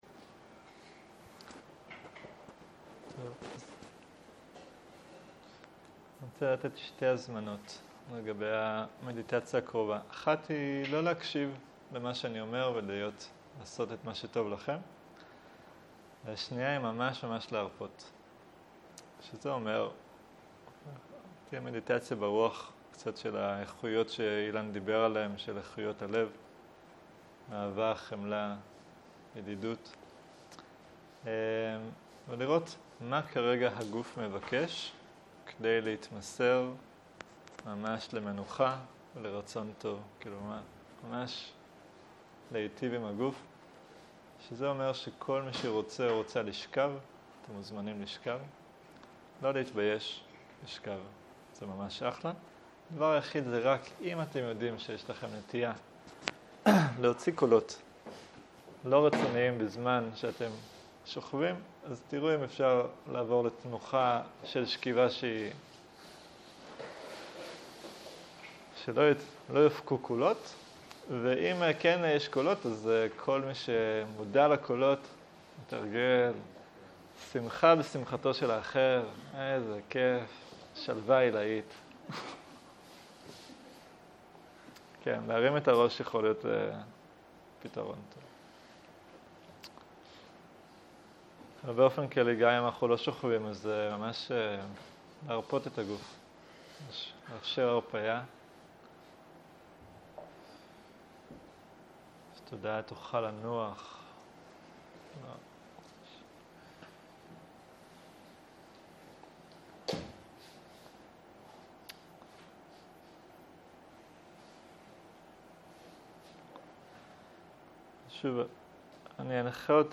ערב - מדיטציה מונחית - מטא